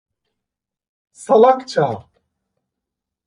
salakça a 🐌 Anlam (İngilizce) Adj Adv Eş anlamlılar saçma A2 Çeviriler عَبِيط blöd idiotisch idiotic idiot stupide idiótico Sıklık C2 (IPA) olarak telaffuz edilir /saˈɫak.t͡ʃa/ Etimoloji (İngilizce) By surface analysis, salak + -ça.